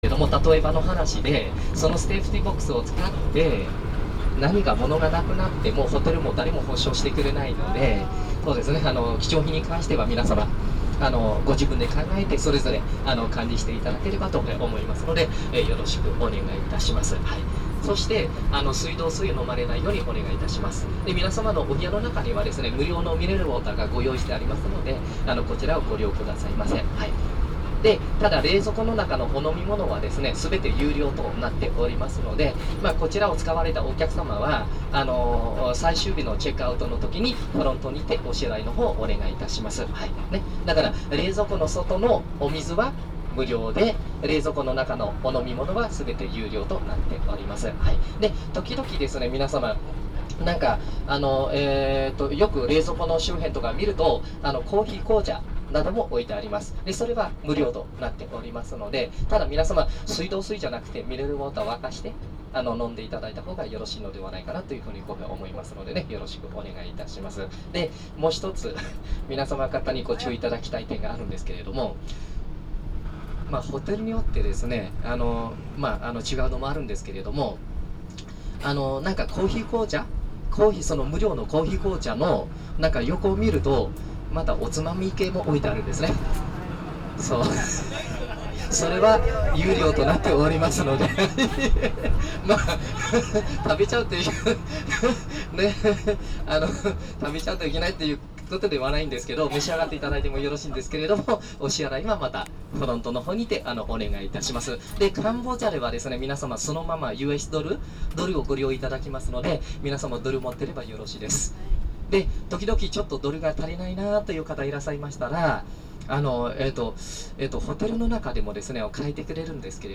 彼の日本語には驚いた聞けば２年位というが　2年でこんなに上手くなるんだ
上の写真をクリックすれば彼の日本語度がわかる